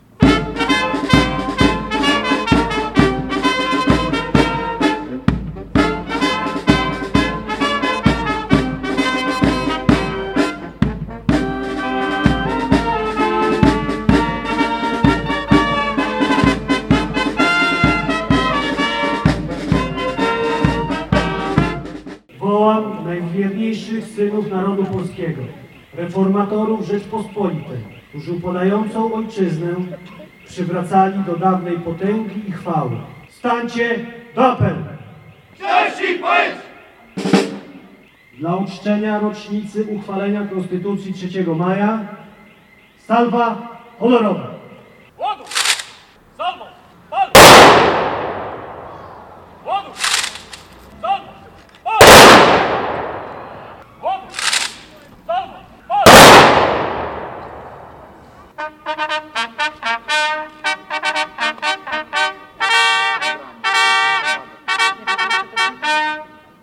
Uroczystości w deszczu